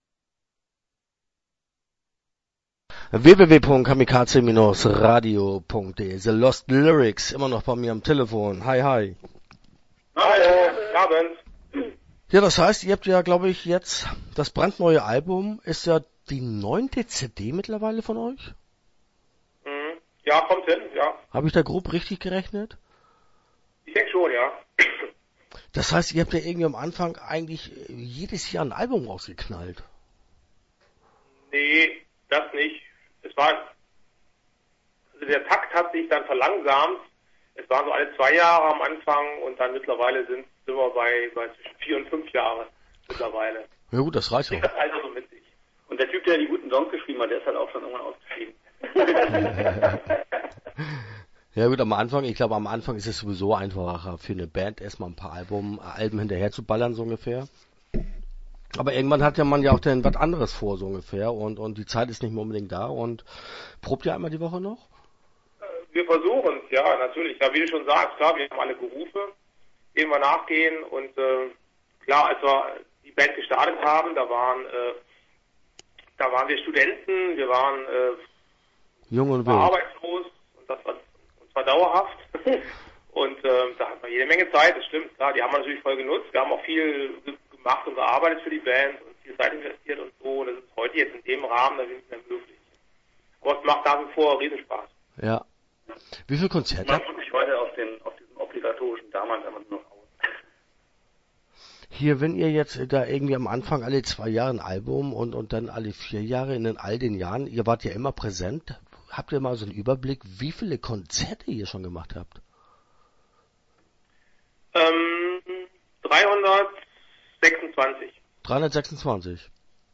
Start » Interviews » The Lost Lycics